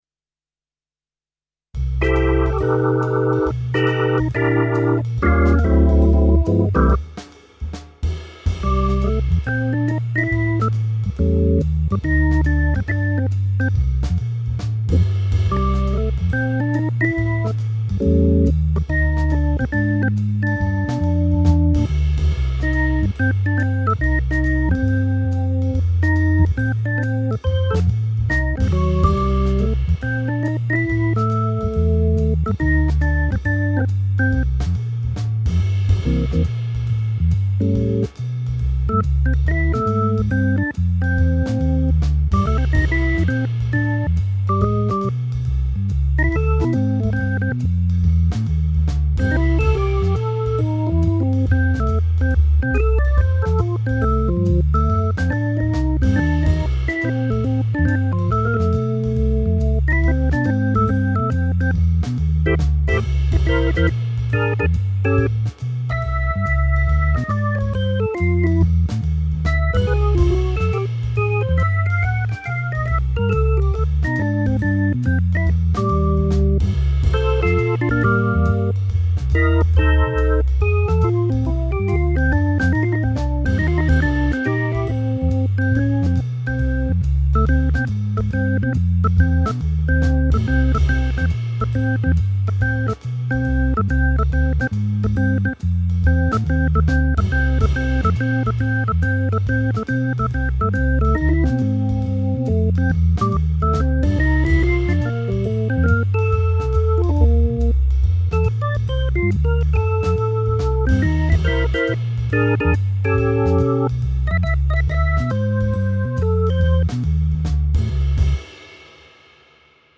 Félicitations... et le rendu a l'orgue est très prometteur.